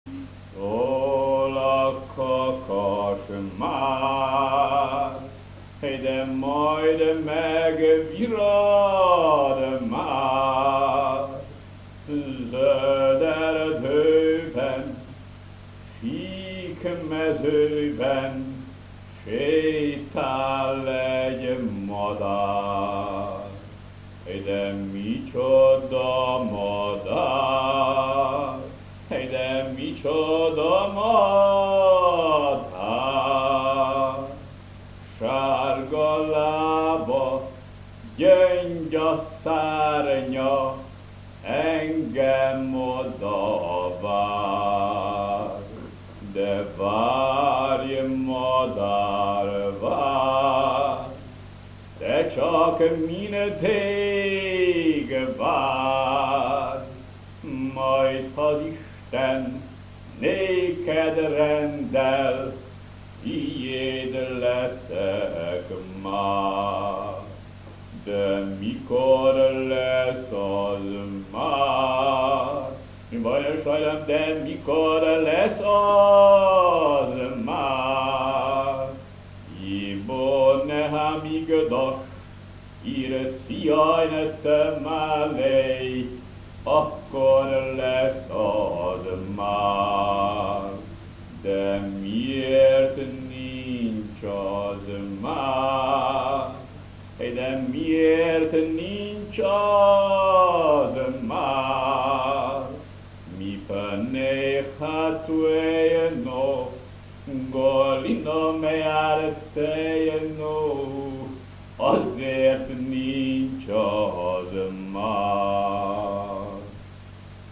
Szol a kakas mar este o melodie hasidica maghiara, compusa de rabinul Isaac Taub (1744 -1828) din Kaliv (Nagykallo), Ungaria.